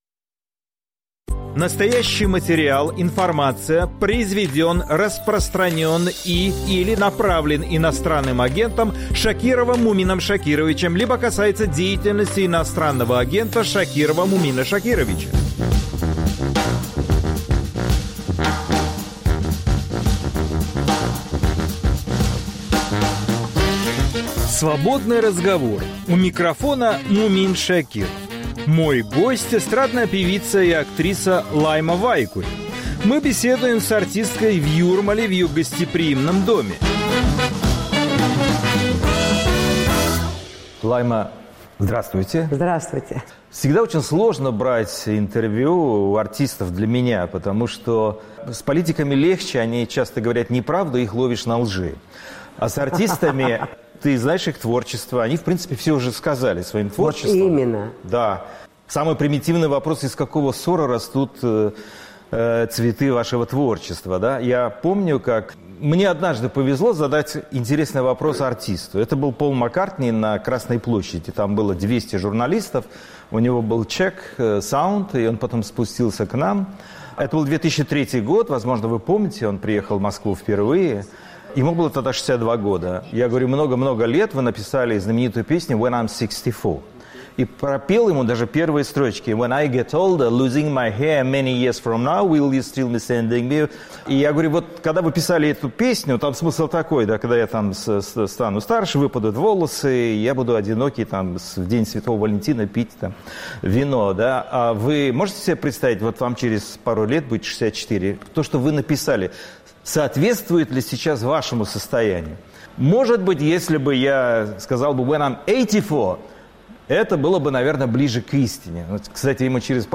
Свободный разговор c певицeй Лаймой Вайкуле